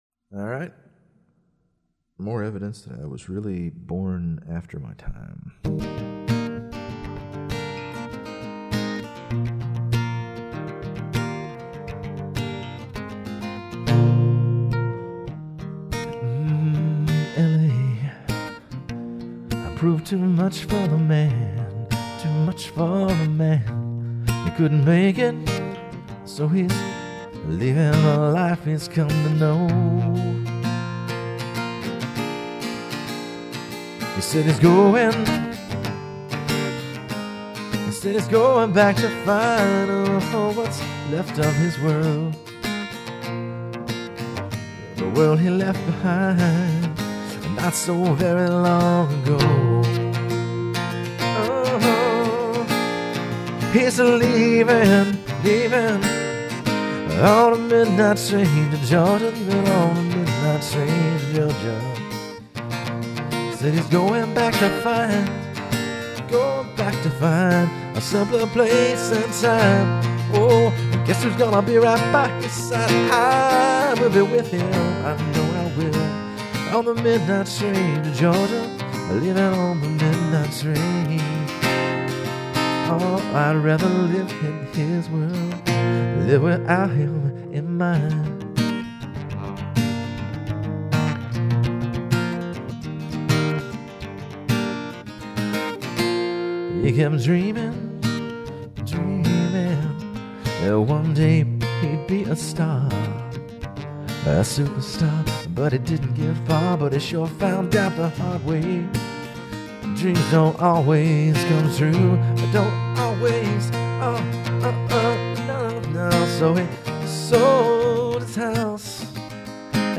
Sounds good.
Oh, also you sound non-gay.